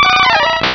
pokeemerald / sound / direct_sound_samples / cries / primeape.aif